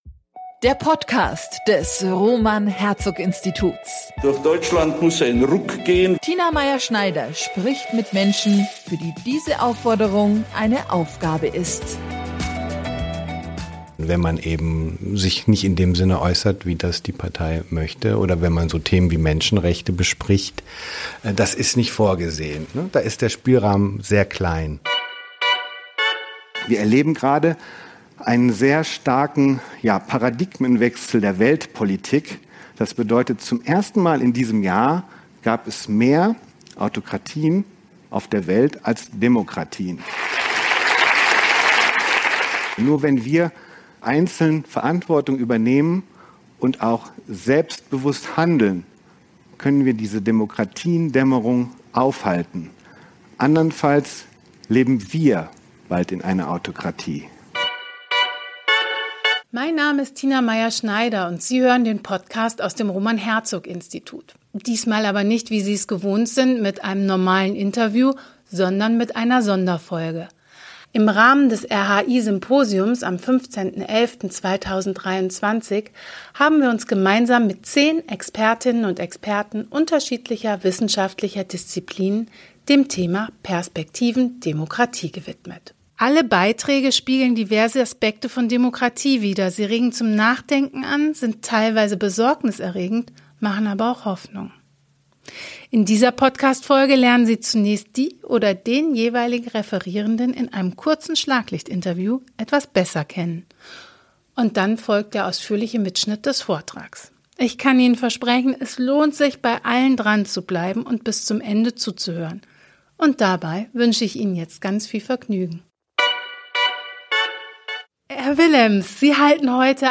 Im Rahmen des RHI-Symposiums am 15. November 2023 haben wir uns gemeinsam mit neun Expertinnen und Experten unterschiedlicher wissenschaftlicher Disziplinen dem Thema „Perspektiven Demokratie“ gewidmet. Ihre Beiträge spiegeln diverse Aspekte von Demokratie wider, sie regen zum Nachdenken an, sind teils durchaus besorgniserregend und machen teils Hoffnung.
Dann folgt der ausführliche Mitschnitt des Vortrags.